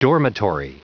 Prononciation du mot dormitory en anglais (fichier audio)
Prononciation du mot : dormitory